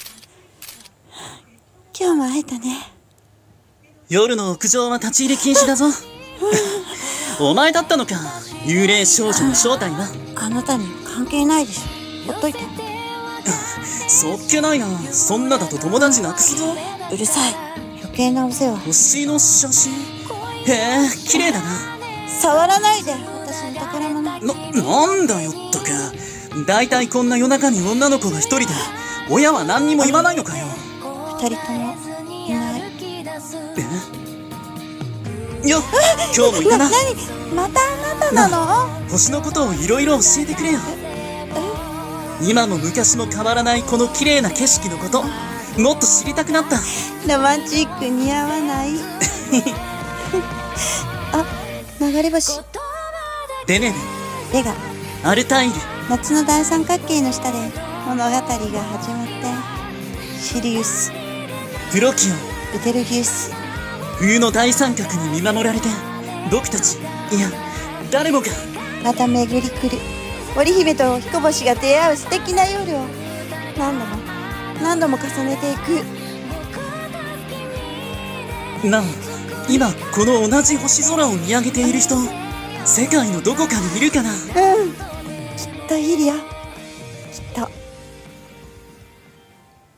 【2人声劇】